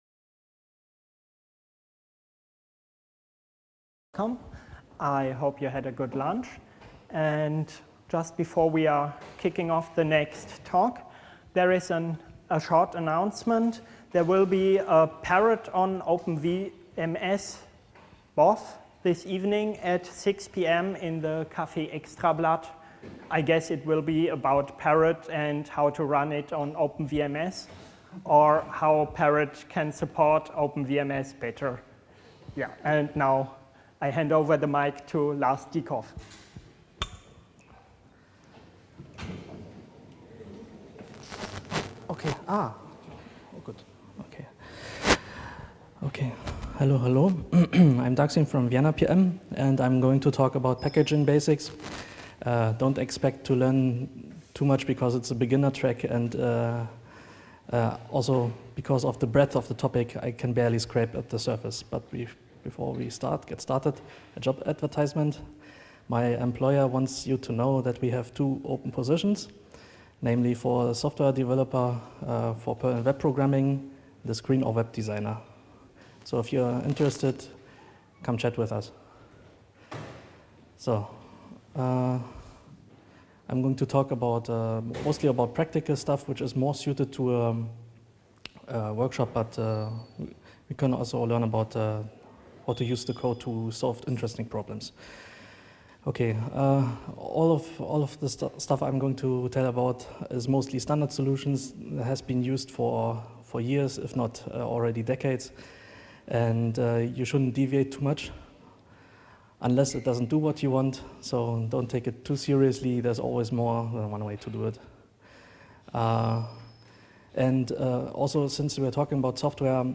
Keynote: Agile Companies Go P.O.P.